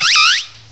sovereignx/sound/direct_sound_samples/cries/snivy.aif at 2f4dc1996ca5afdc9a8581b47a81b8aed510c3a8